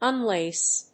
音節un・lace 発音記号・読み方
/`ʌnléɪs(米国英語)/